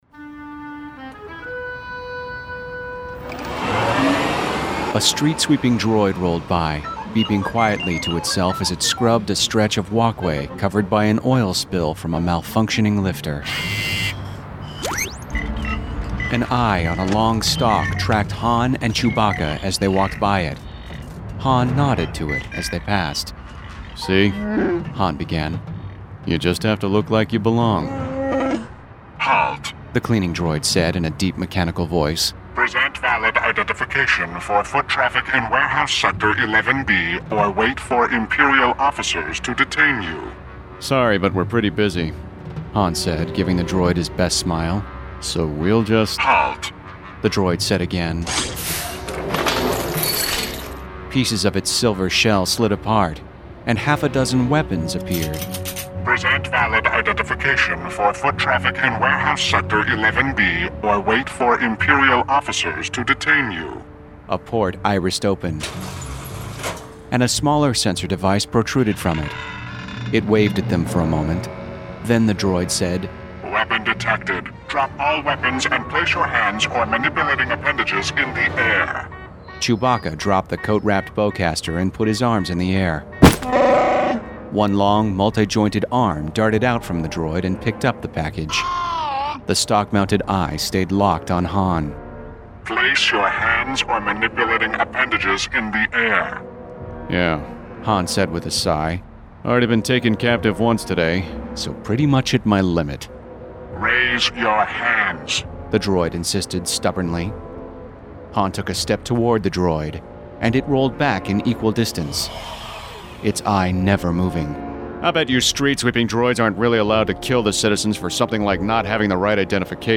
star-wars-honor-among-thieves-audio-book-clip.mp3